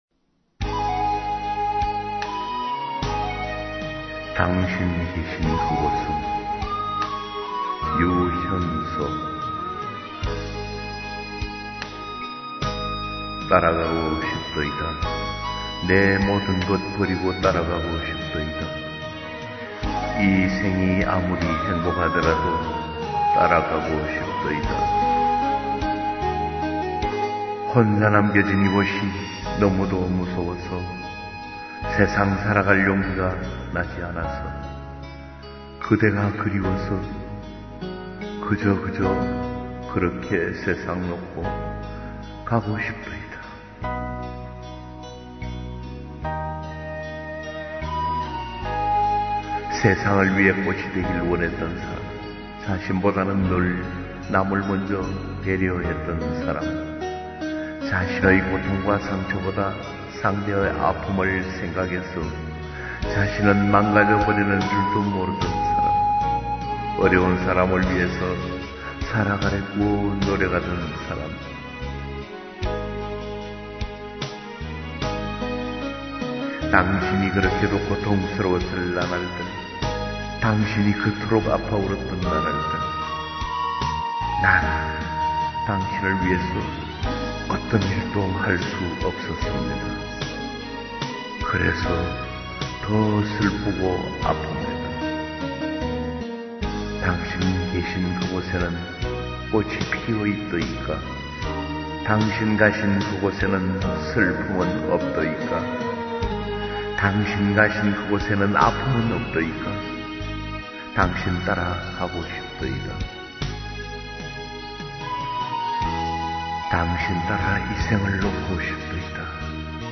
☆ 고운 낭송시